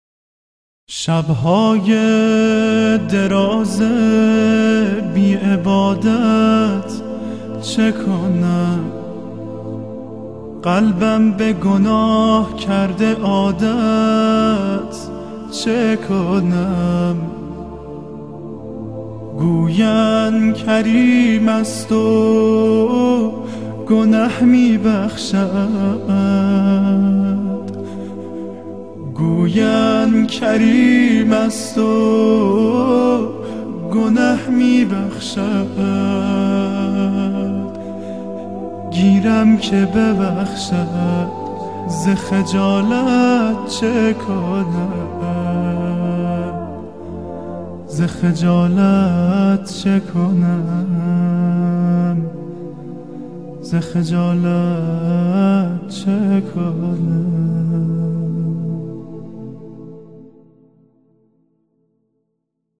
نکته ی قابل توجه اینکه بدون موسیقیست...